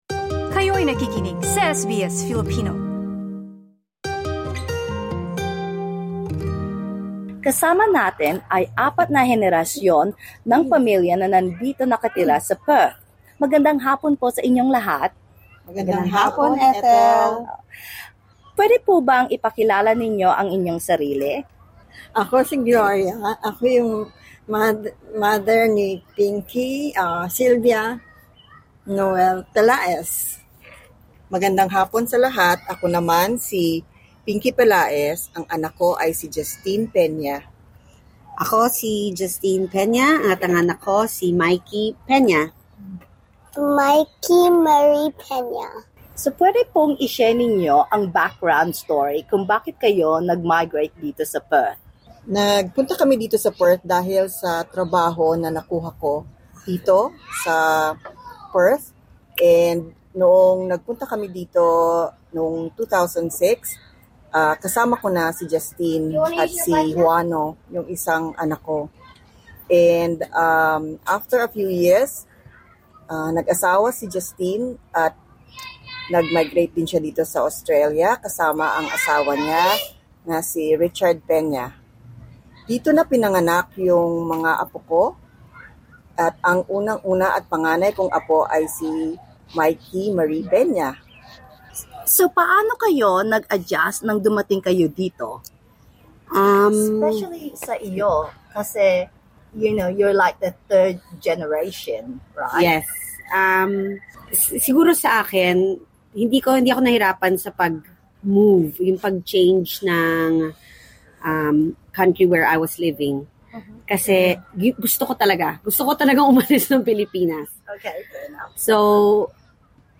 Apat na henerasyon ng pamilya ang nakapanayam ng SBS Filipino kaugnay sa pagpapalaki ng bata gamit pa din ang Filipino values.